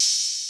Pony Open Hat_2.wav